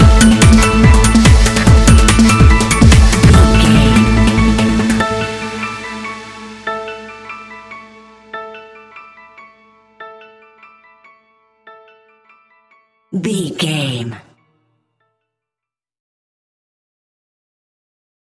Ionian/Major
Fast
driving
energetic
uplifting
hypnotic
industrial
drum machine
piano
synthesiser
acid house
electronic
uptempo
synth leads
synth bass